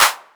808Clap.wav